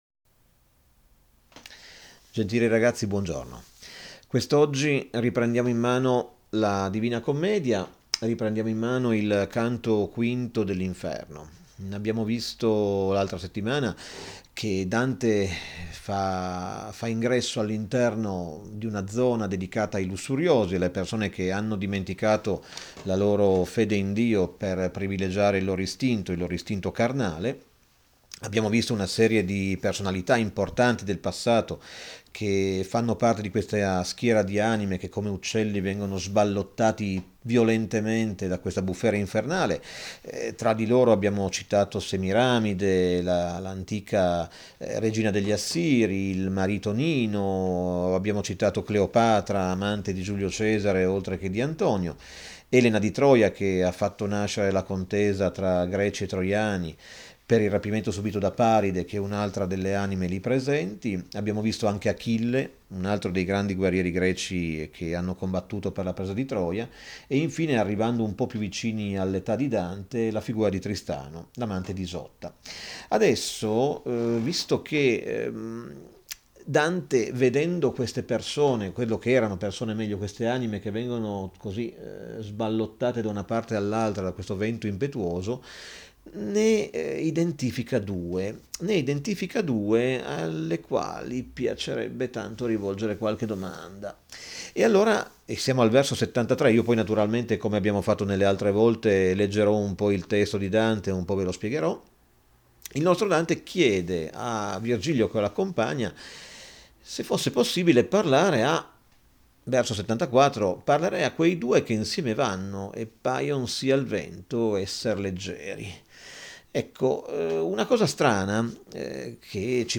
All’interno di questo file audio, le classi terze potranno ascoltare la spiegazione e la lettura dei versi finali del Quinto canto dell’Inferno dantesco (che si legge qui).